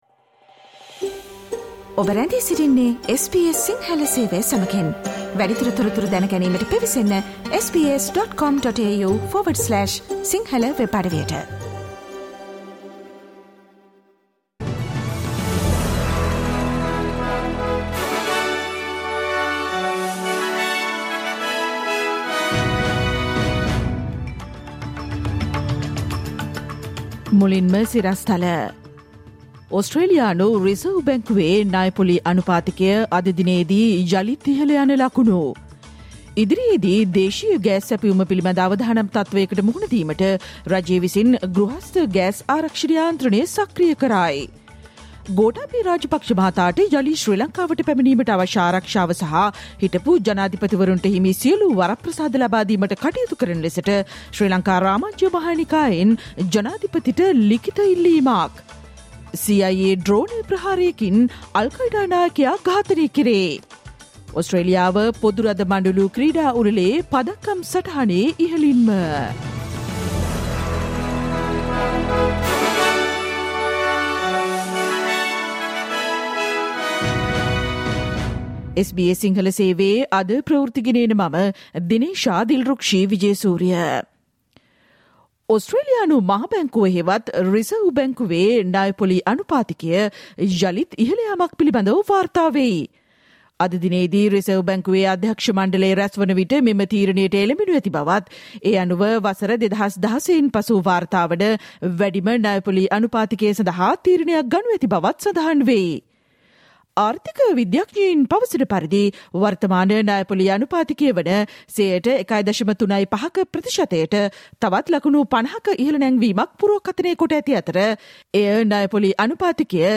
Click on the speaker icon on the image above to listen to the SBS Sinhala Radio news bulletin on Tuesday 02 August 2022